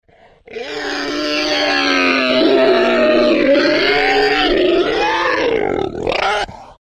Alien Scream; Angry Or Anguished Creature Vocals.